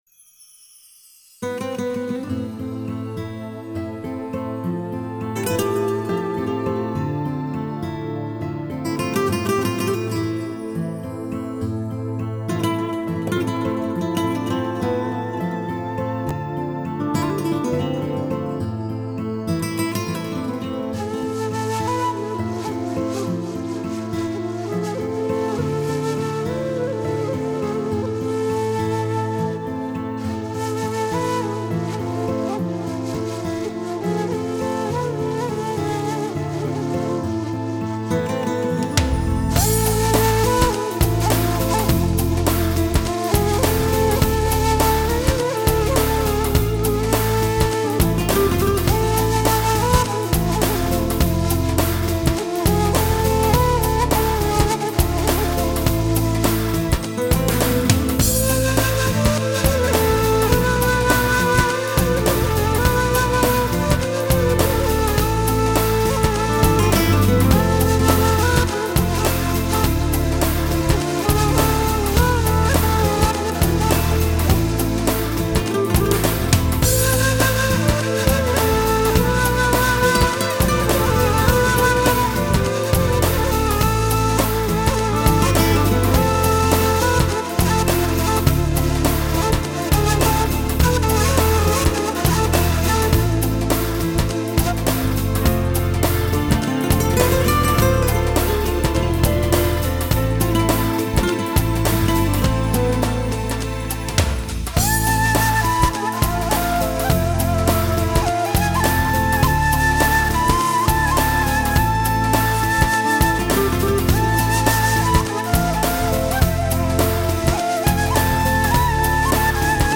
Genre: Oriental, Ethnic, World, Duduk, New Age
guitar
kamancha